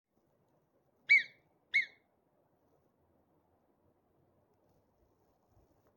Marmots at the Grossglockner
Marmots are especially famous for their warning calls or whistles.
As soon as the marmots noticed dogs, they let out their characteristic whistles and fled.